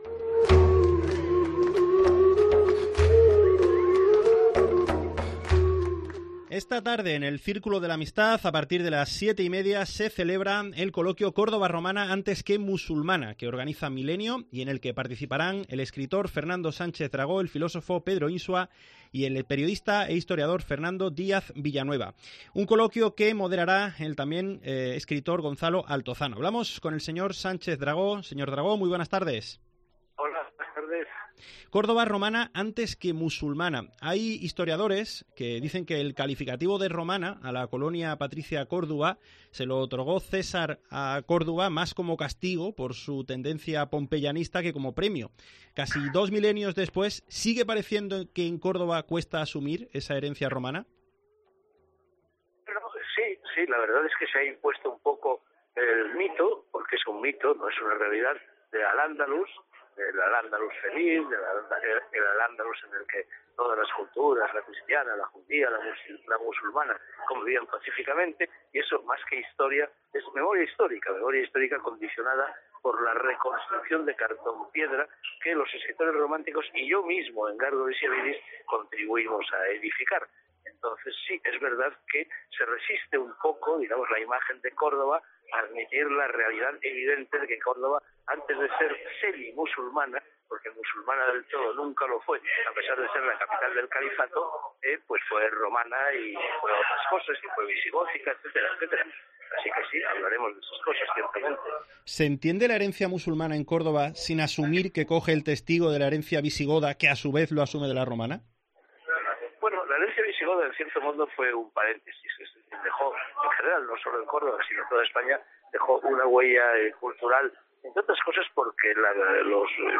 Entrevista a Fernando Sánchez Dragó en COPE Córdoba
Atiende a COPE Córdoba Fernando Sánchez Dragó.